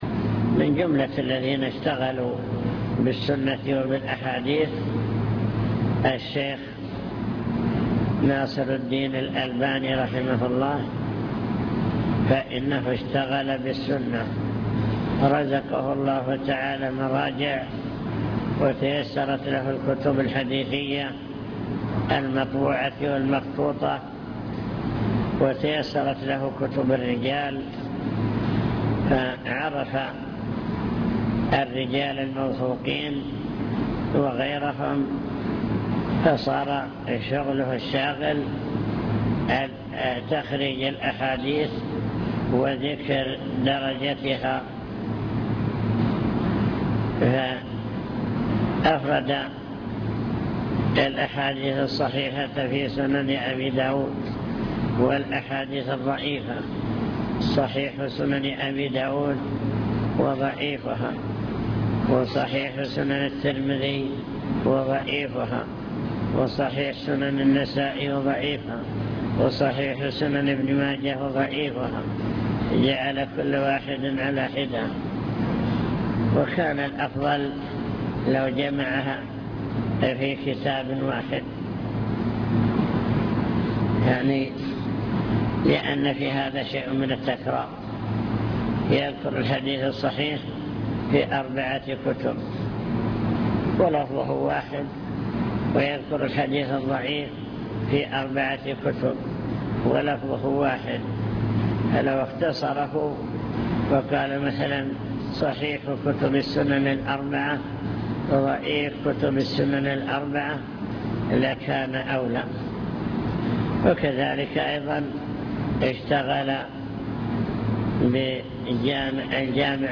المكتبة الصوتية  تسجيلات - محاضرات ودروس  محاضرات بعنوان: عناية السلف بالحديث الشريف